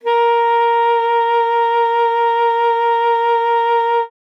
42e-sax08-a#4.wav